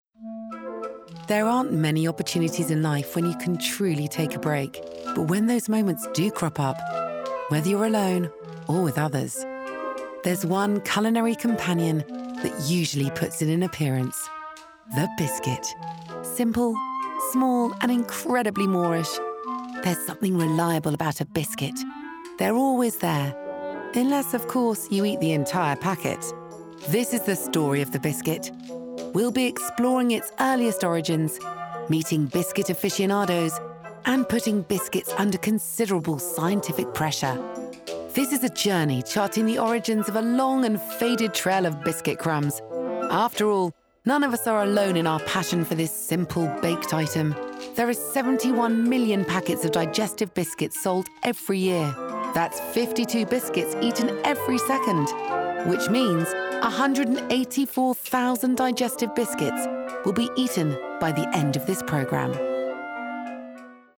30/40's Neutral, Assured/Soothing/Husky
• Documentary